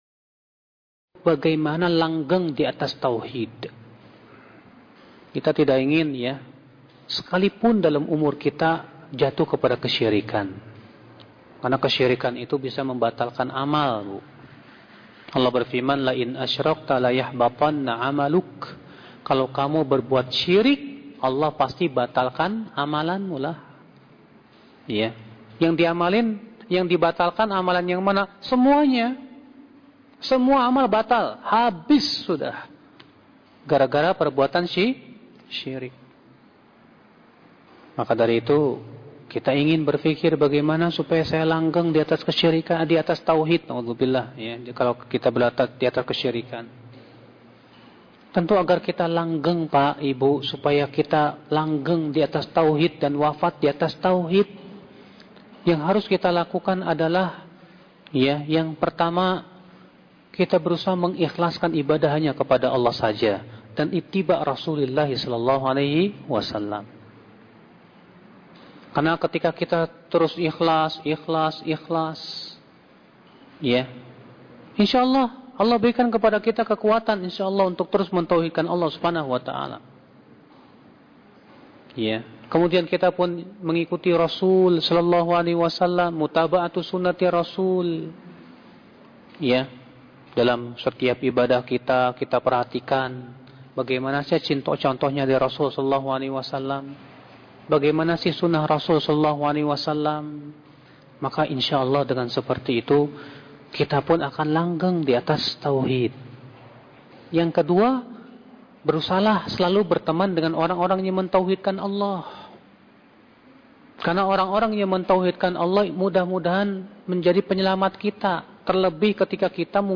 Kajian Audio